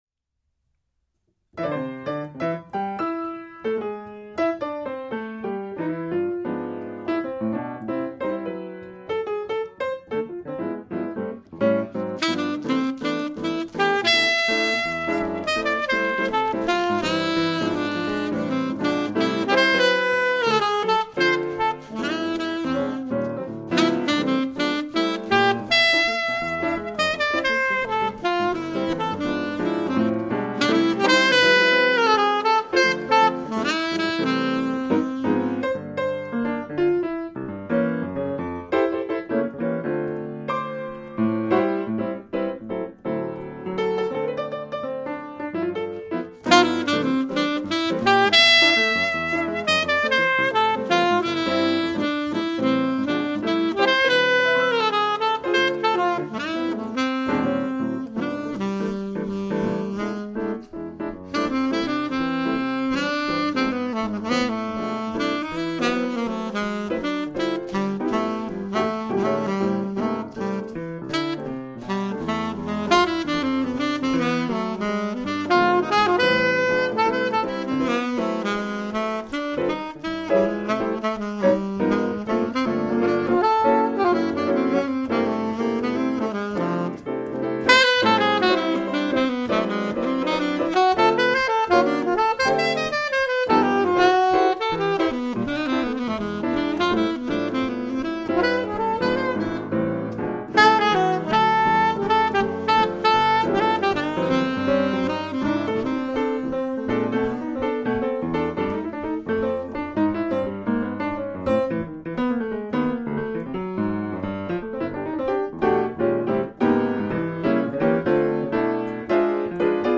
An exceptionally talented jazz duo who perform instrumental versions of jazz standards on the piano and saxophone.
Instrumental Jazz Duo
Piano, Saxophone